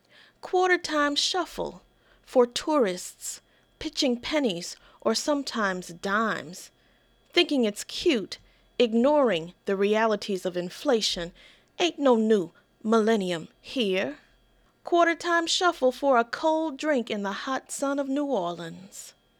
CD Audio Book (Spoken Word/Music)